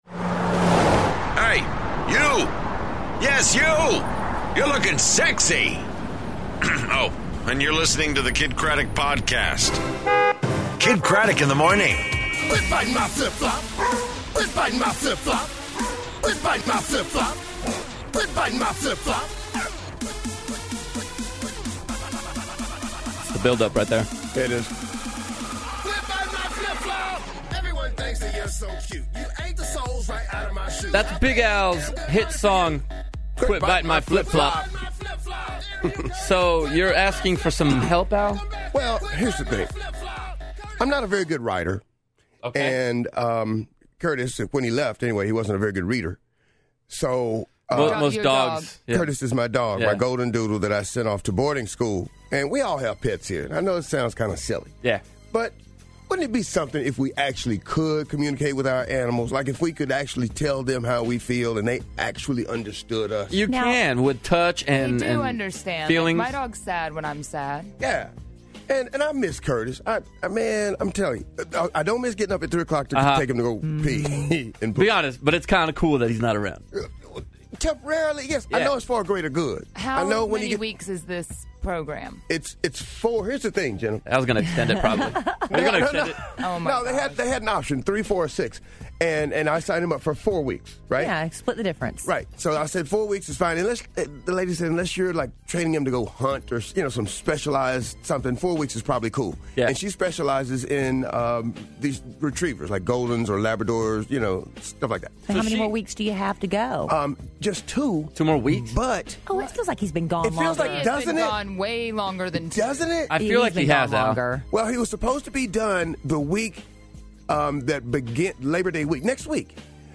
Brenton Thwaites From The Giver In Studio, Reuniting A Father And Daughter, And An App To Make Your Kids Call You Back.